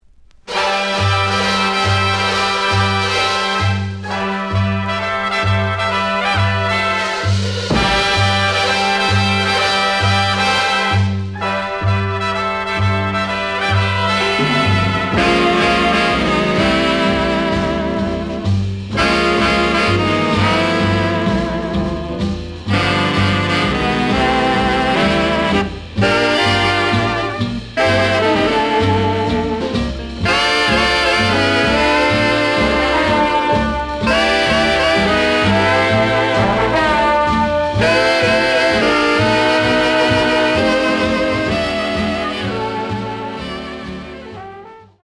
The audio sample is recorded from the actual item.
●Format: 7 inch
●Genre: Big Band